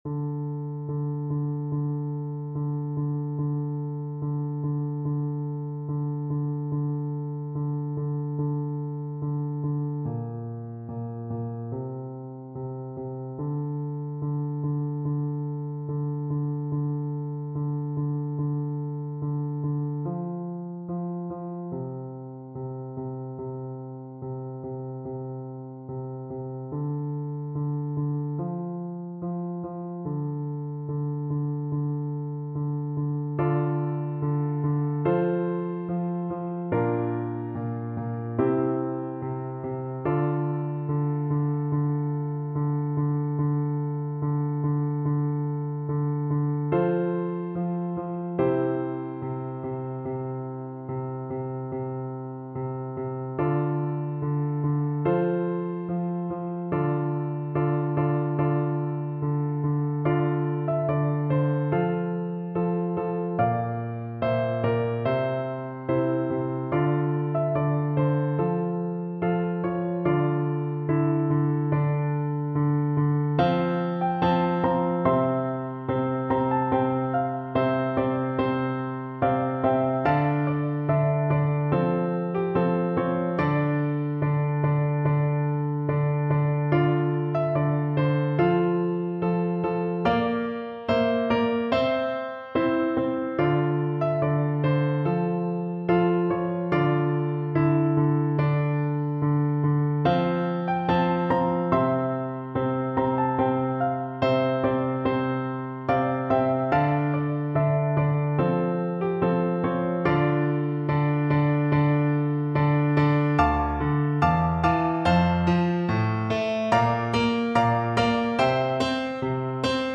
Flute
Traditional Music of unknown author.
4/4 (View more 4/4 Music)
D minor (Sounding Pitch) (View more D minor Music for Flute )
Moderato =c.100